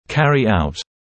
[‘kærɪ aut][‘кэри аут] выполнять, осуществлять, проводить